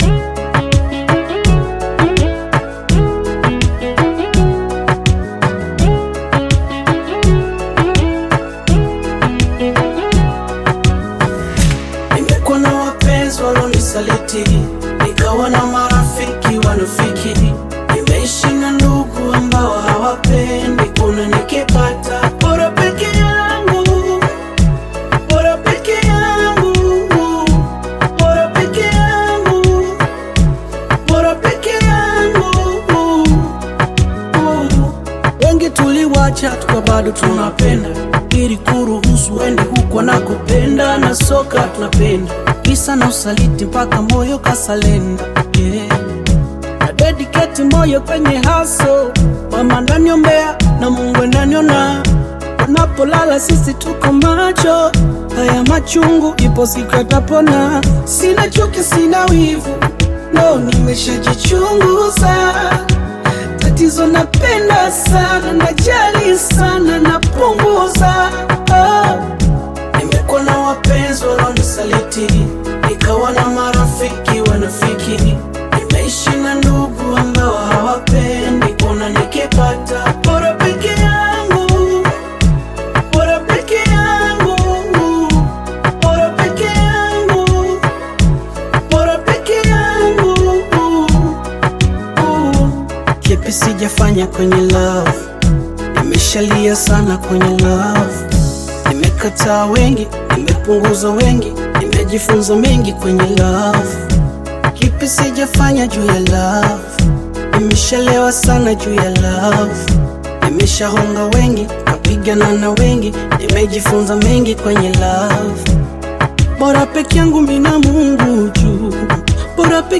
Bongo Flava music track
Tanzanian Bongo Flava artist, singer, and songwriter